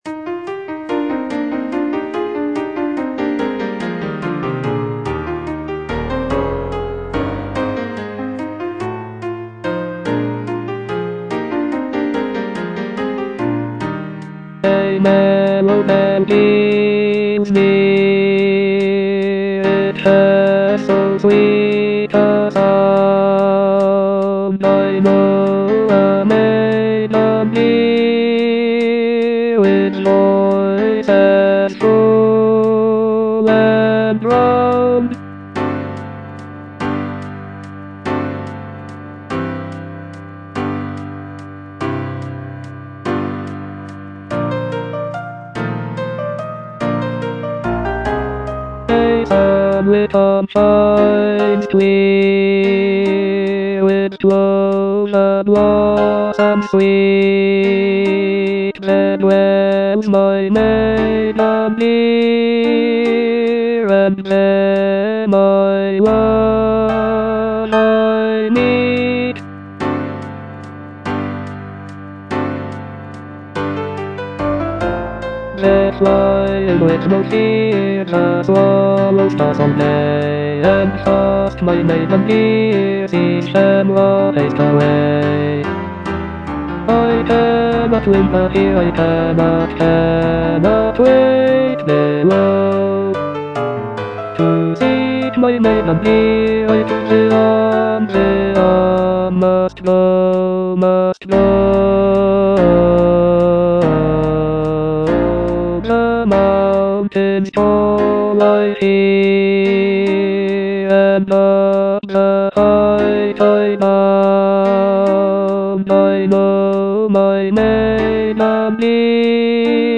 E. ELGAR - FROM THE BAVARIAN HIGHLANDS On the alm (bass I) (Voice with metronome) Ads stop: auto-stop